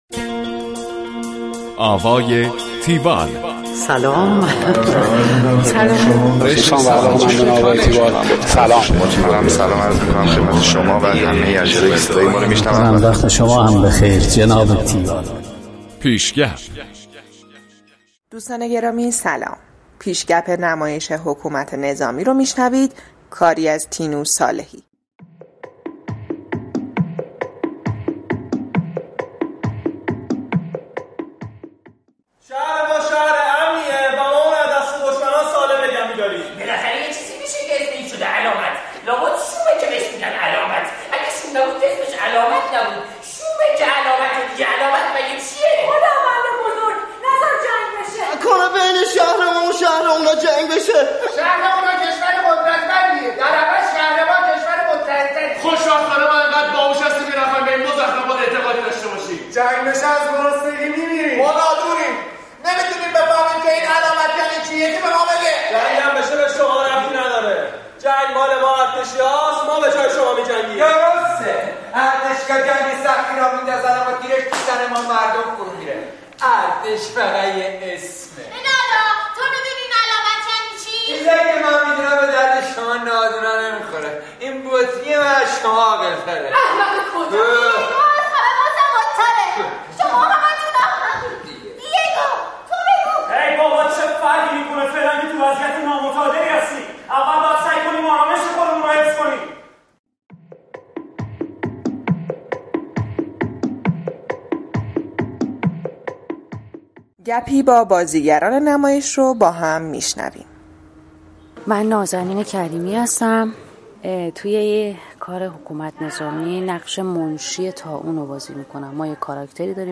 گزارش آوای تیوال از نمایش حکومت نظامی